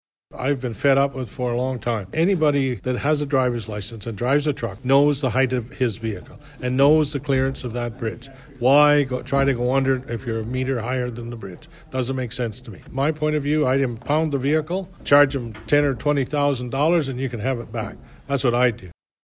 Mayor Jim Harrison tells Quinte News, enough is enough.